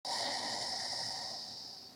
HippoExhale.wav